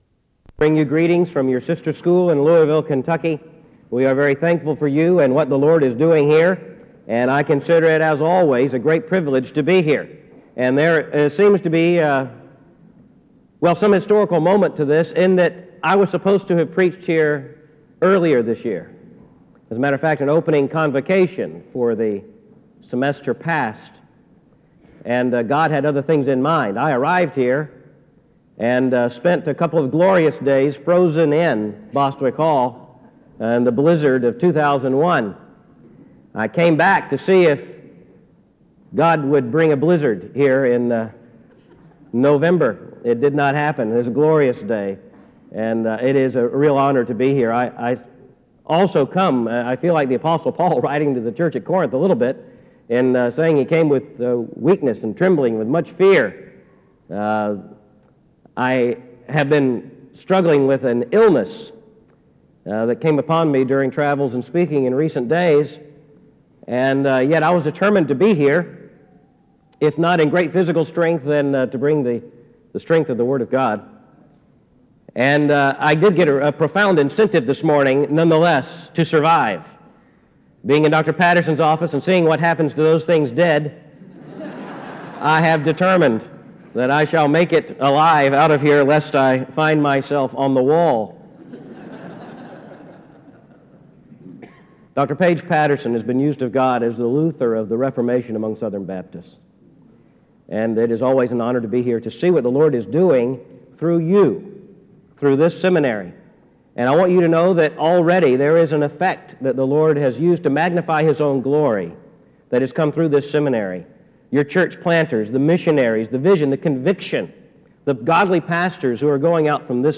SEBTS Chapel - R. Albert Mohler, Jr. November 7, 2001